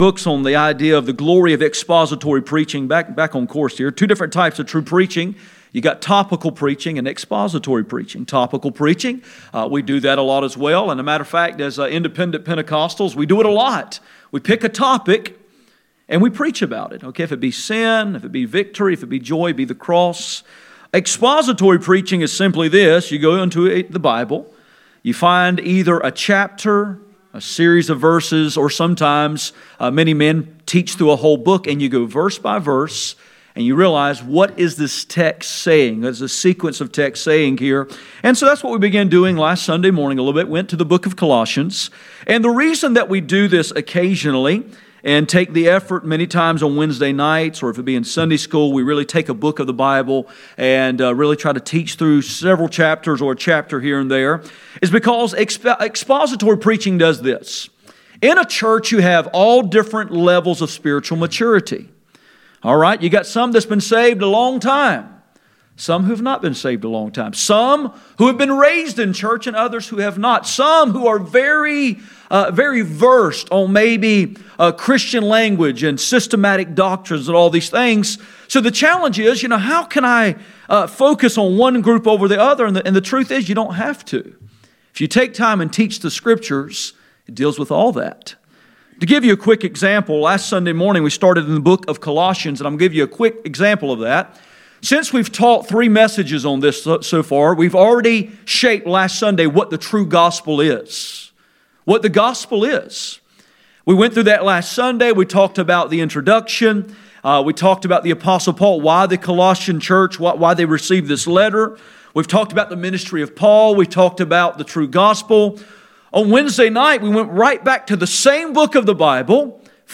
None Passage: Colossians 1:15-20 Service Type: Sunday Morning %todo_render% « The Preeminence of Christ The Preeminence of Christ